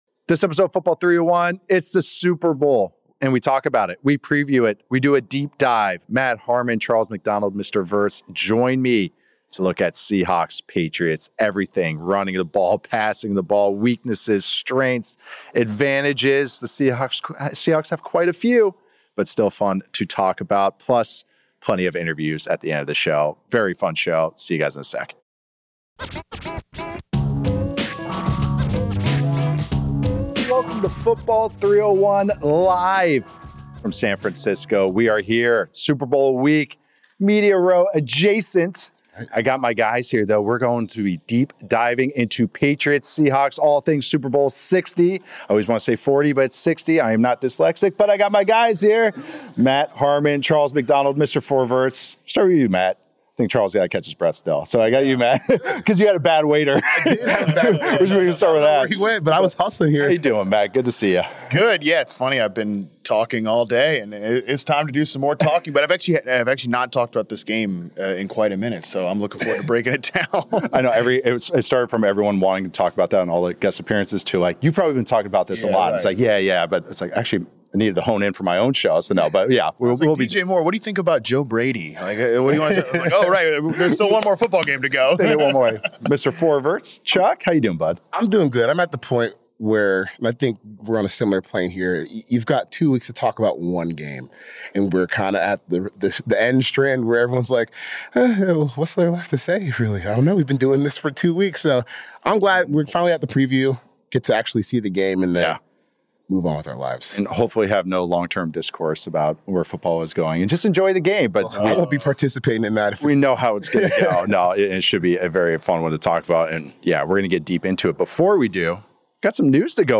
The show wraps up with conversations with Josh Allen, Jayden Daniels & Bijan Robinson.